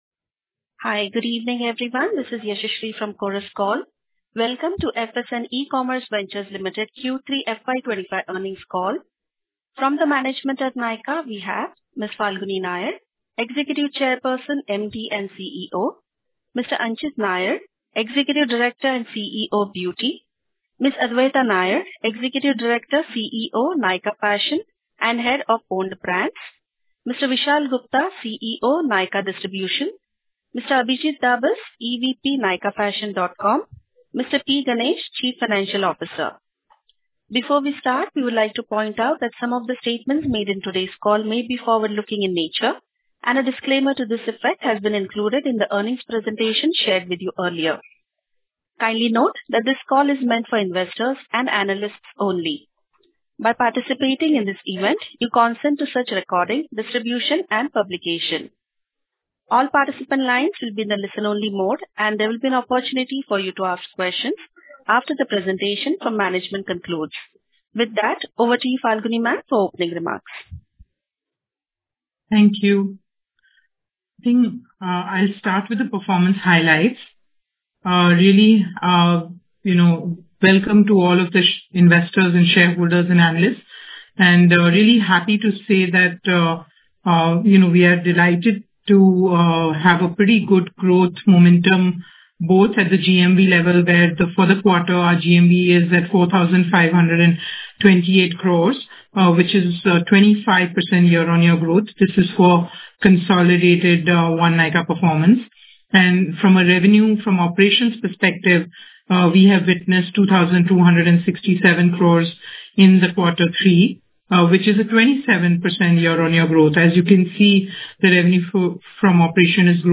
Concalls
Q3-FY25-Earnings-Call-Audio-Recording.mp3